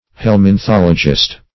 Search Result for " helminthologist" : The Collaborative International Dictionary of English v.0.48: Helminthologist \Hel`min*thol"o*gist\, n. [Cf. F. helminthologiste.] One versed in helminthology.